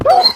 wolf_hurt1.ogg